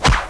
1 channel
bow.wav